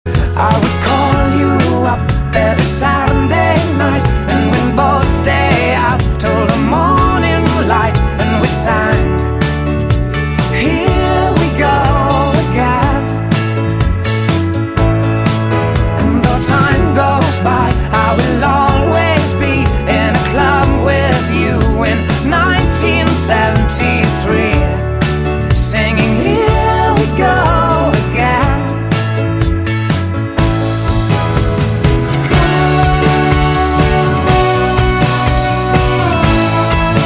M4R铃声, MP3铃声, 欧美歌曲 57 首发日期：2018-05-15 18:36 星期二